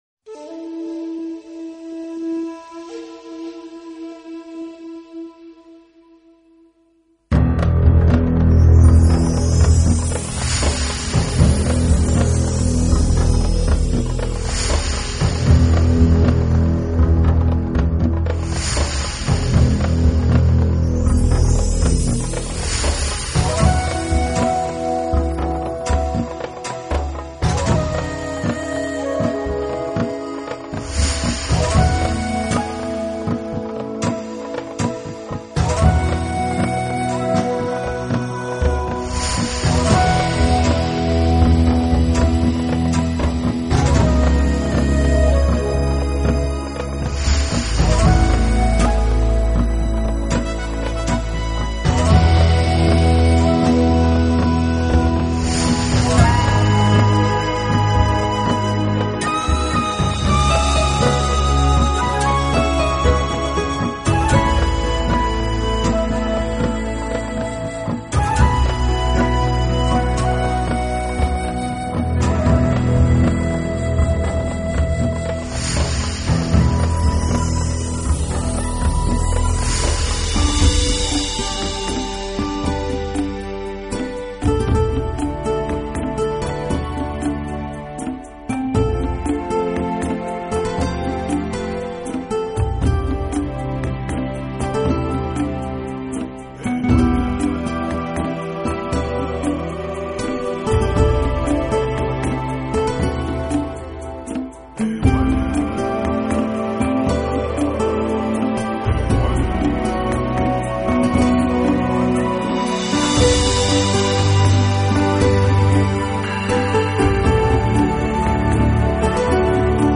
音乐类型: New Age / Celtic